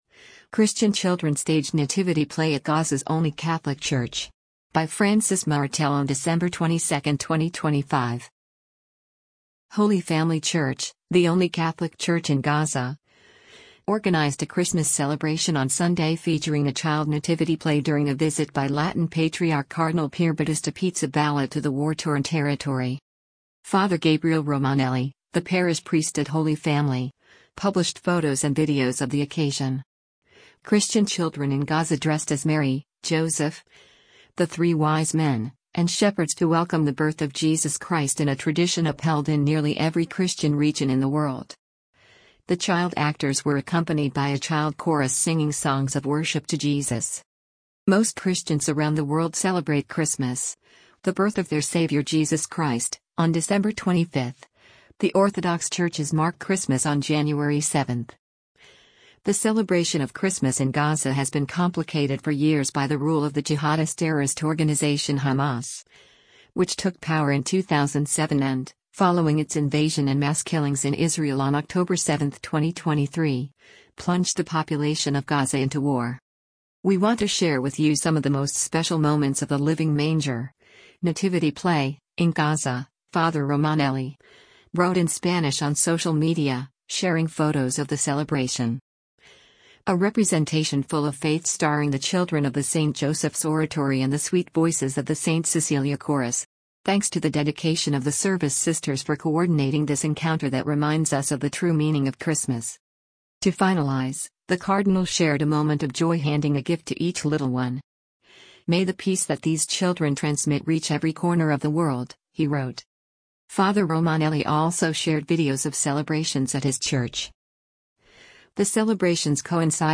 Holy Family Church, the only Catholic church in Gaza, organized a Christmas celebration on Sunday featuring a child nativity play during a visit by Latin Patriarch Cardinal Pierbattista Pizzaballa to the war-torn territory.
The child actors were accompanied by a child chorus singing songs of worship to Jesus.